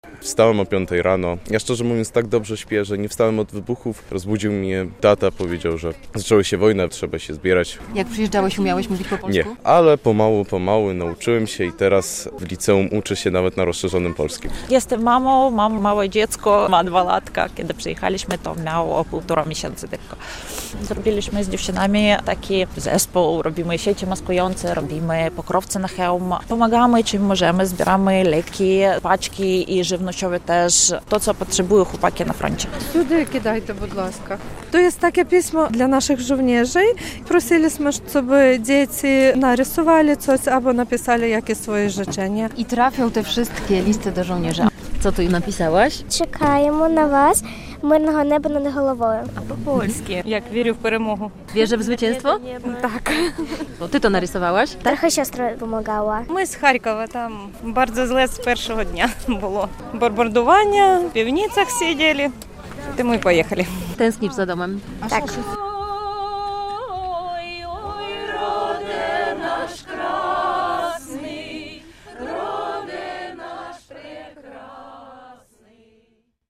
W Białymstoku grupa Ukrainek zorganizowała akcję wspierającą żołnierzy - relacja